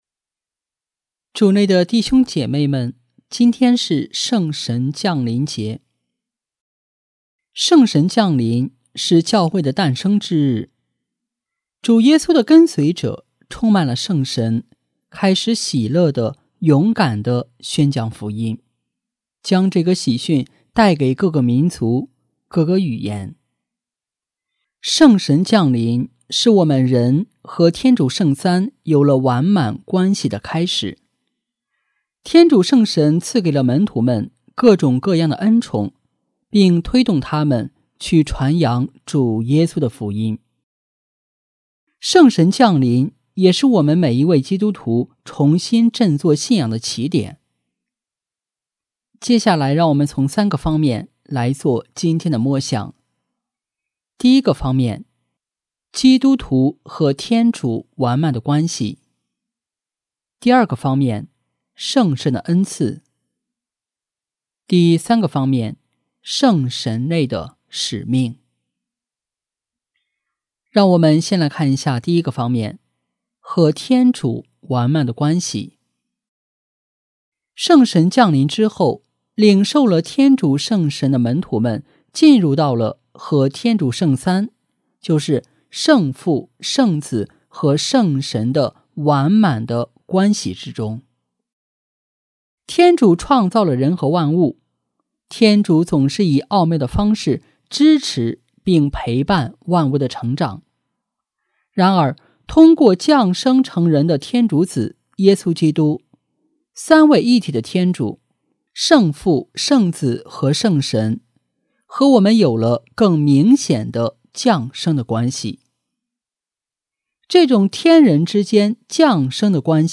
【主日证道】| 圣神内的使命（圣神降临节）